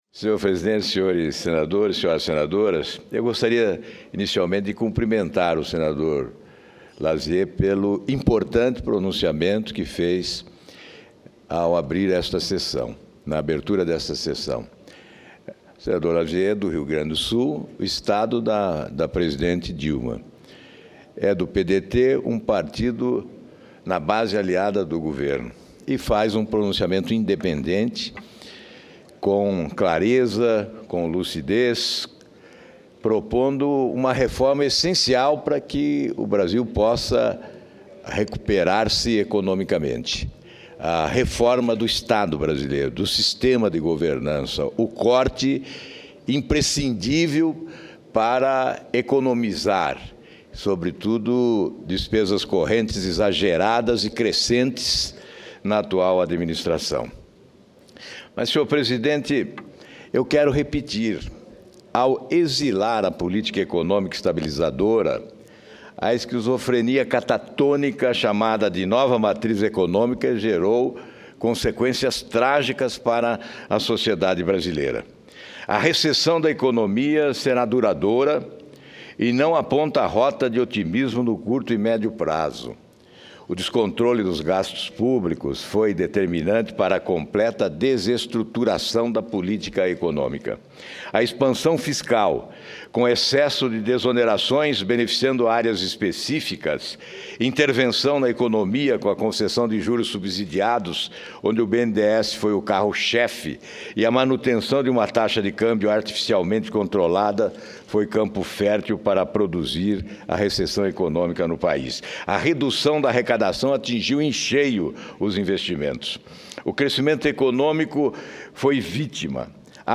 Plenário
Discursos